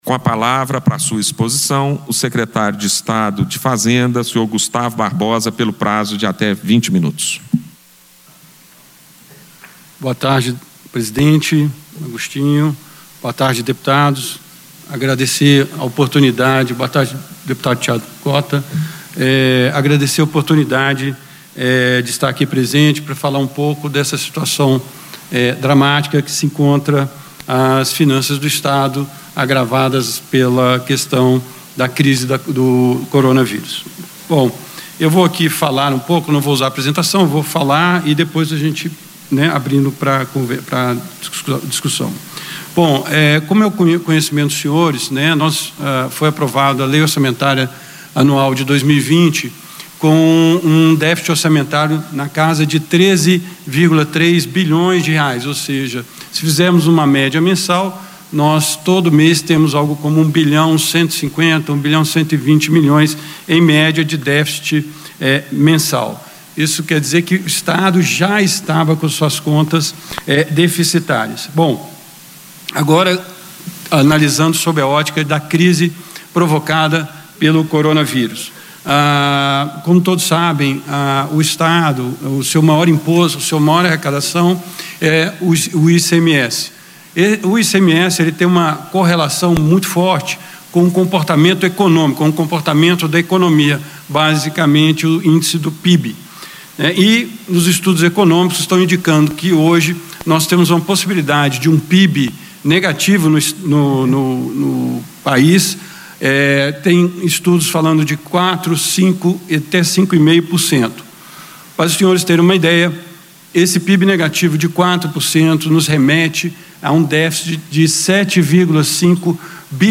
A íntegra do pronunciamento, durante audiência no Plenário, esclarece qual é a situação econômica do estado, agravada pela crise financeira causada pela pandemia daCovid-19.
Gustavo Barbosa, Secretário de Estado da Fazenda